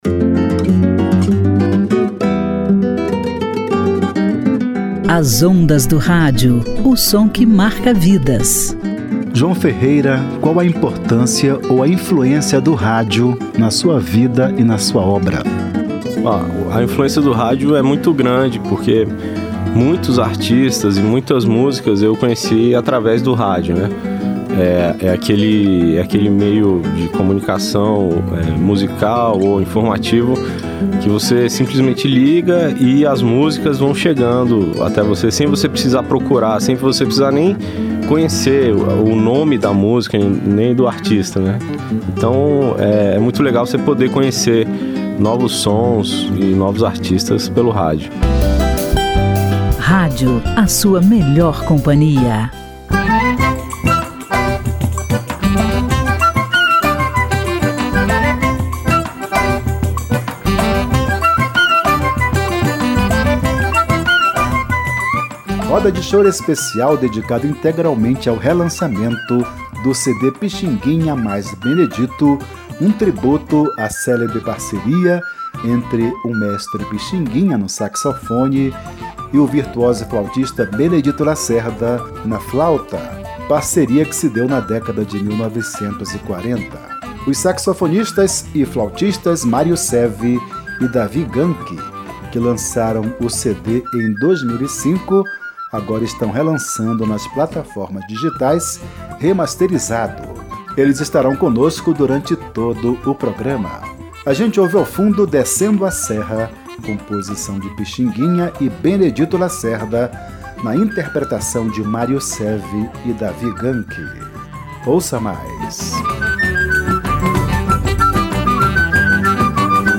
saxofone
flauta
saxofonistas e flautistas
remasterizado.